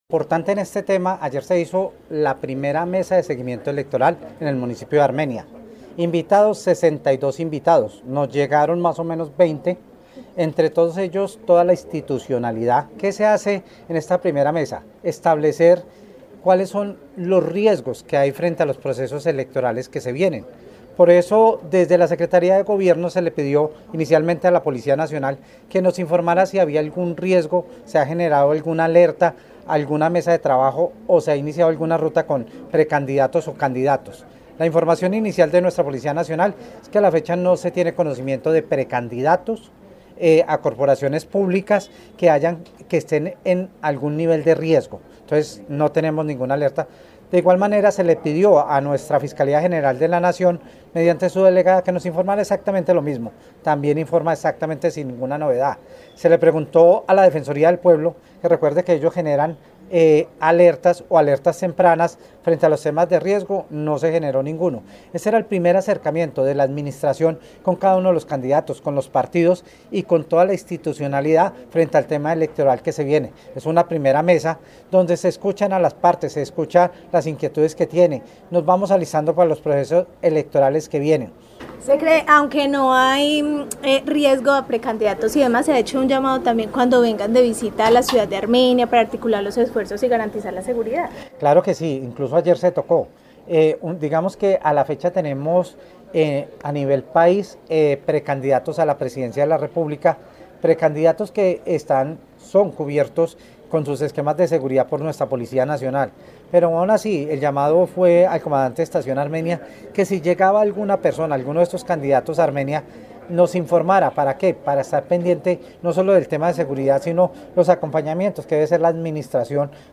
Secretario de Gobierno de Armenia, Carlos Arturo Ramírez